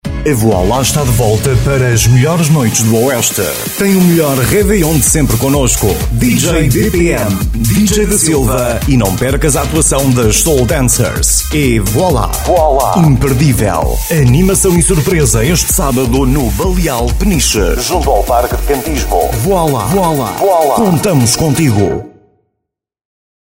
Sprechprobe: Werbung (Muttersprache):
His voice has been described as warm, smooth, sophisticated, natural and youthful.